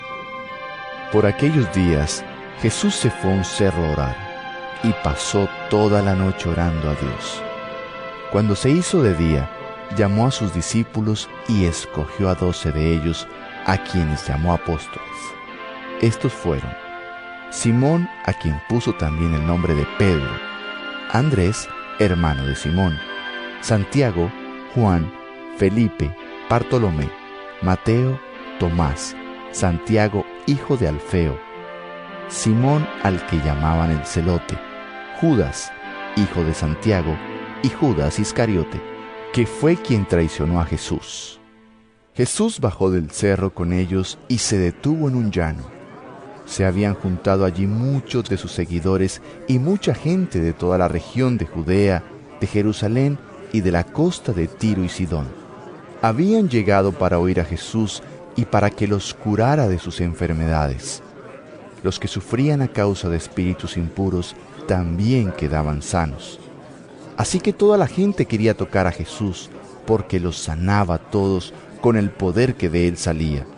Lc 6 12-19 EVANGELIO EN AUDIO